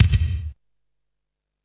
• 2000s Large Room Kickdrum Sound E Key 131.wav
Royality free kickdrum sound tuned to the E note. Loudest frequency: 802Hz